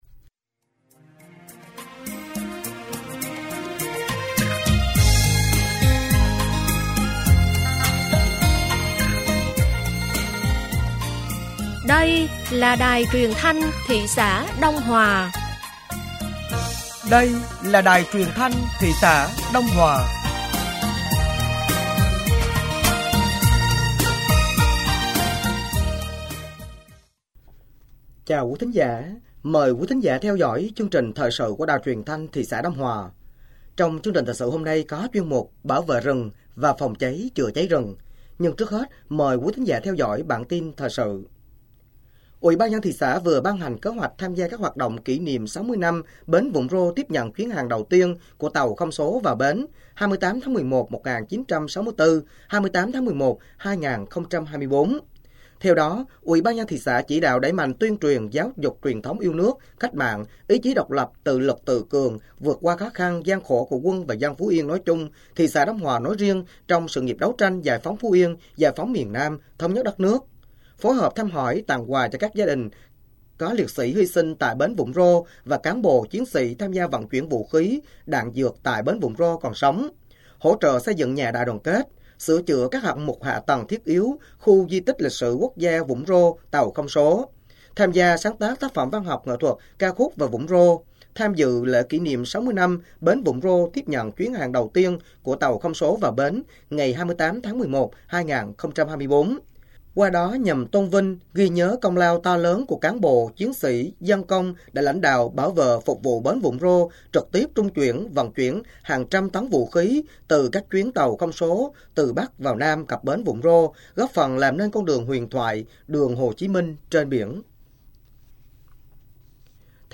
Thời sự tối ngày 04 và sáng ngày 04 tháng 8 năm 2024